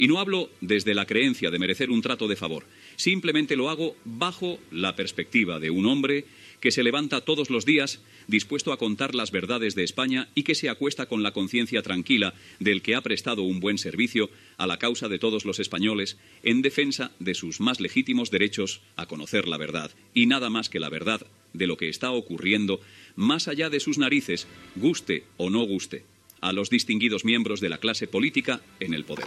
Comentari per expressar el desacord sobre les concessions d'emissores d'FM per part del govern espanyol.
Info-entreteniment